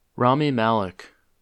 Rami Said Malek (English: /ˈrɑːmi ˈmælɪk/
En-us-Rami_Malek.oga.mp3